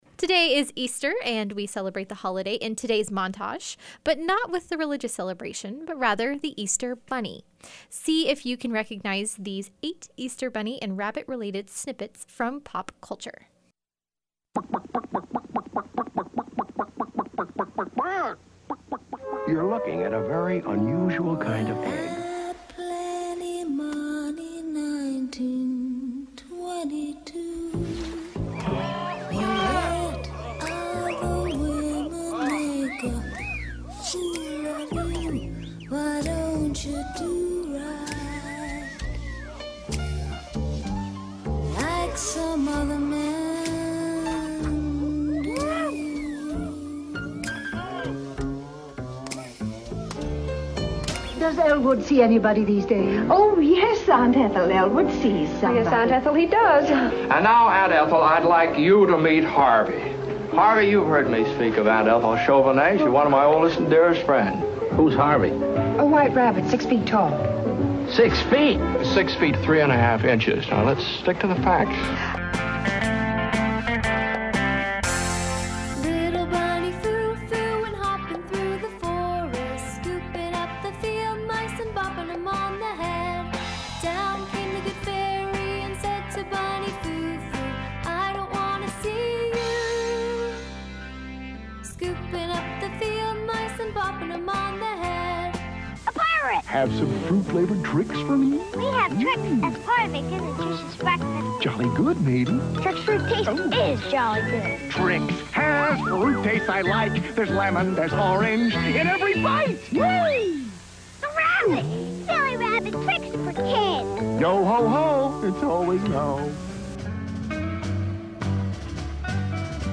Here are the selections for today's pop culture montage celebrating the Easter Bunny.